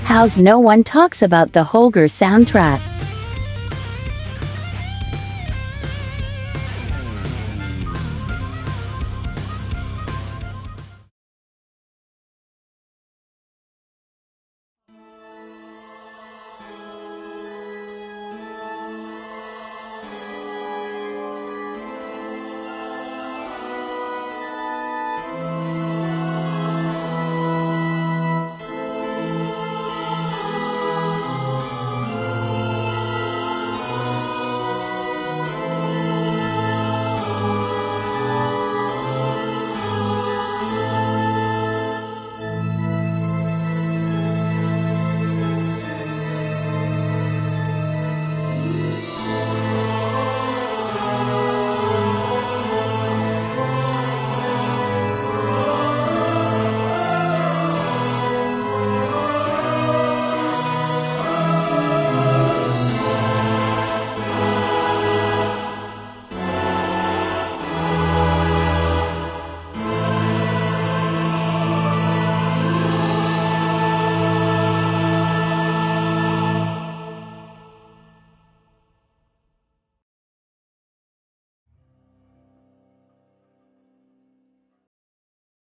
الساوندتراك